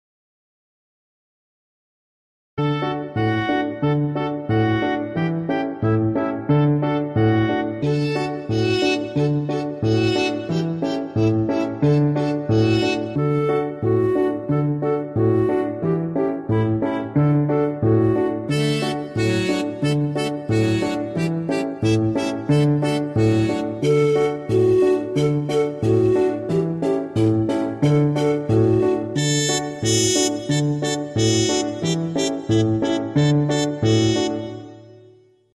Караоке.